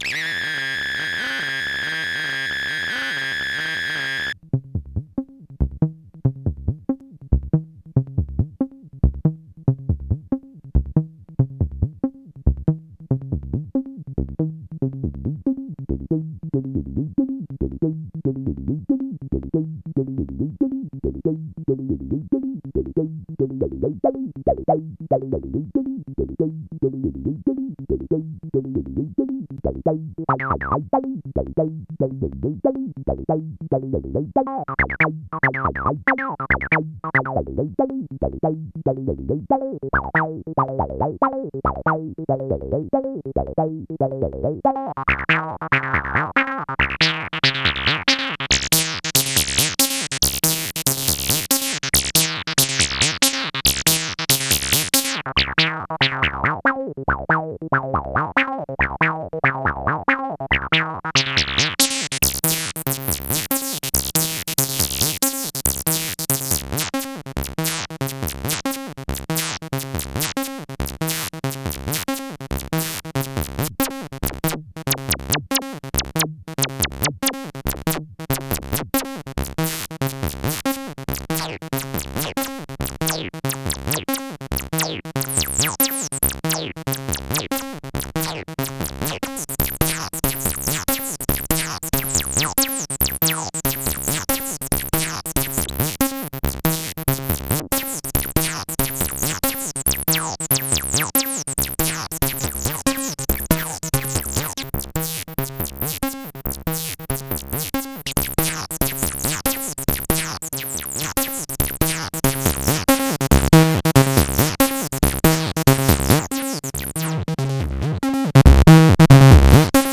(pretty subtle use of mods here: bitcrusher-like sound, subtle filter mod effects, laser blasts, drippy squelches, thin broken speakers, rippy zipper, accent distortion demonstrated)
As you can see, many of the effects are subtle, they're still x0xb0x, but twisted